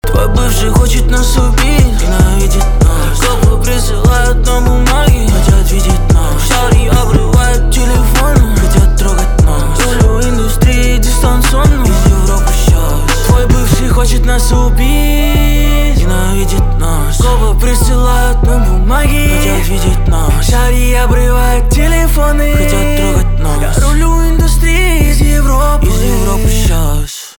русский рэп , битовые , басы